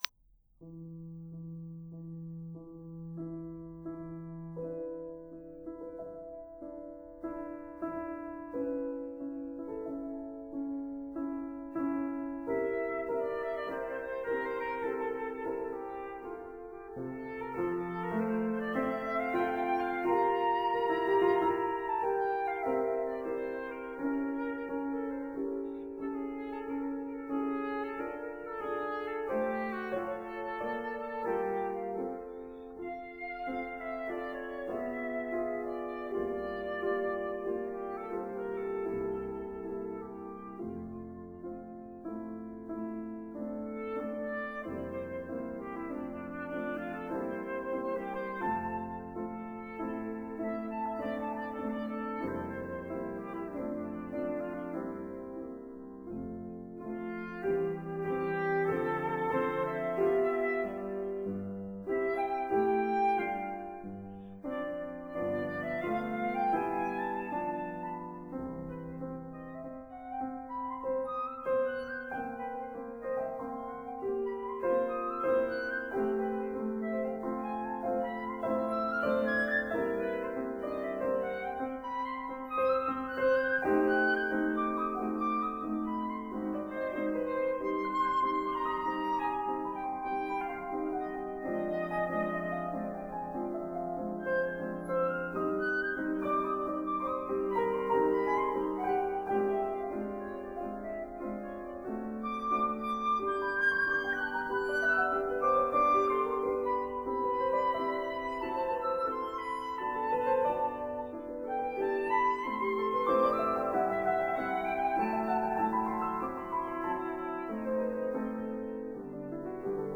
flute
piano